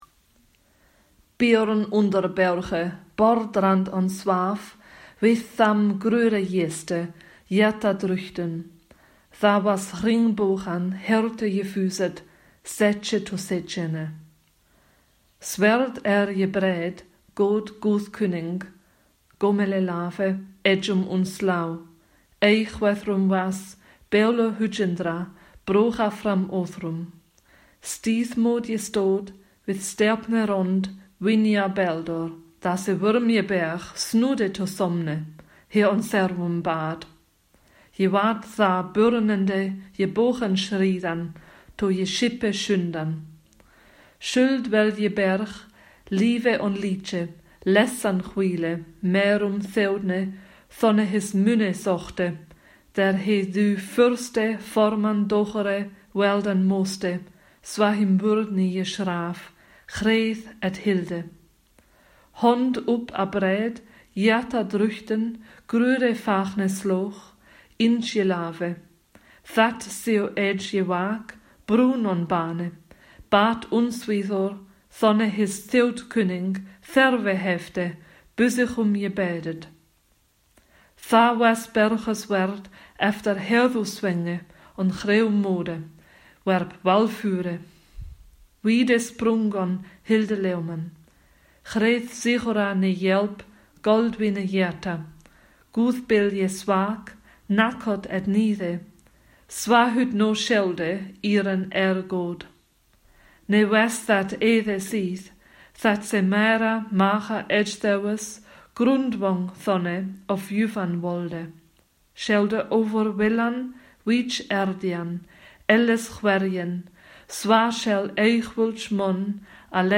Old English Core Vocabulary: Pronunciation
Soundfiles of Old English Texts being read aloud: